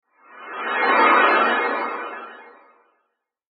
Short Sci-Fi Transition Sound Effect
Experience a fast sci-fi flyby sound in a futuristic space style. Use it as a smooth whoosh transition between video clips or scenes.
Short-sci-fi-transition-sound-effect.mp3